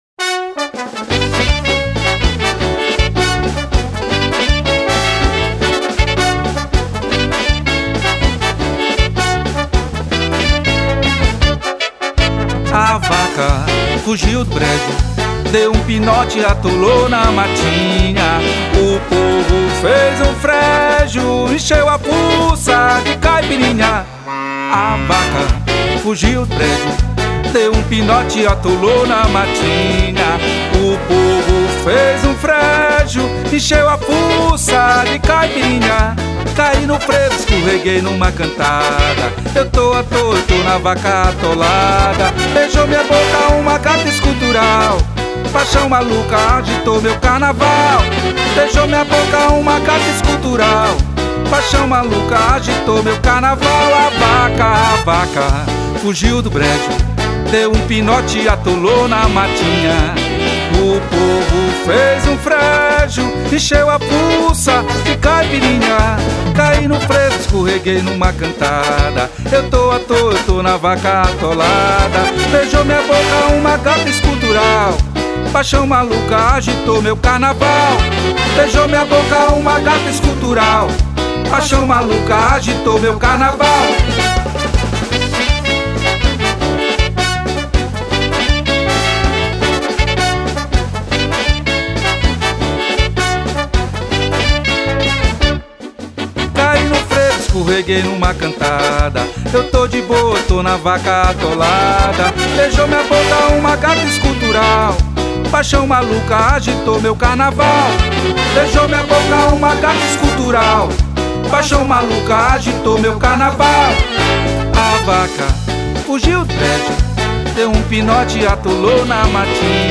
602   02:59:00   Faixa:     Frevo